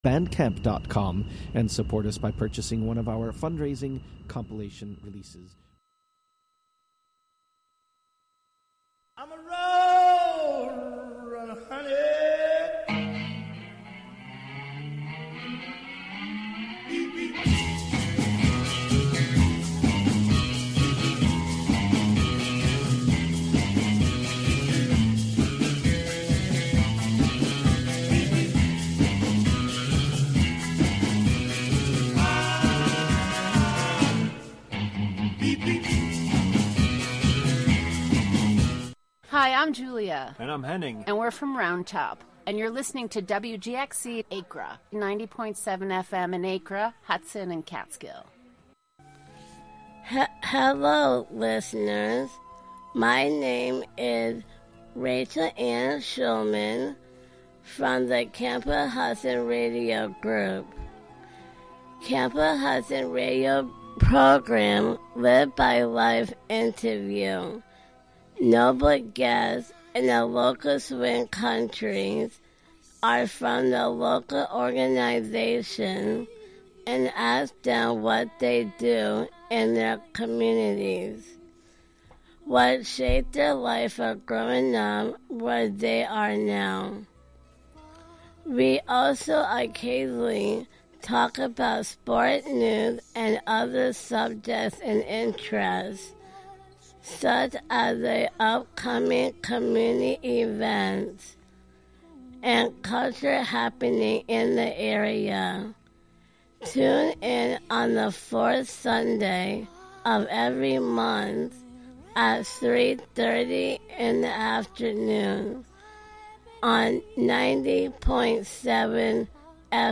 brings you sounds from raves and clubs around the world